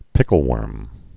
(pĭkəl-wûrm)